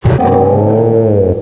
pokeemerald / sound / direct_sound_samples / cries / heatmor.aif